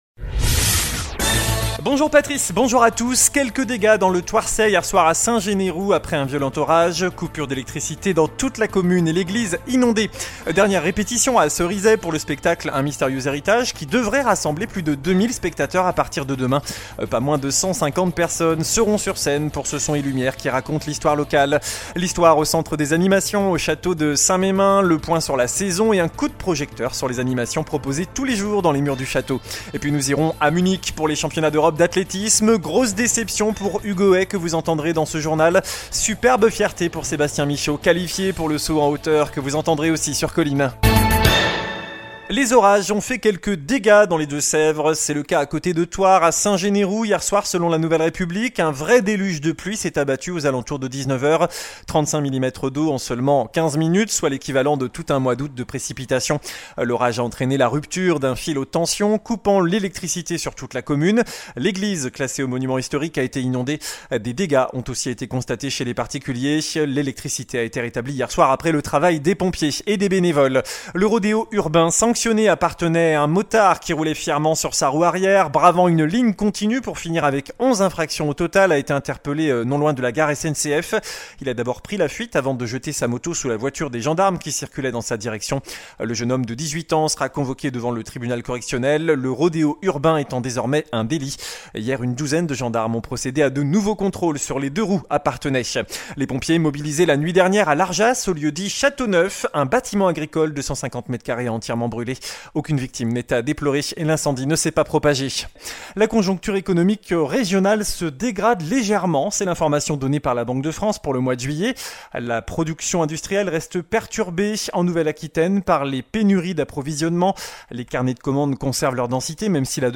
JOURNAL DU MERCREDI 17 AOÛT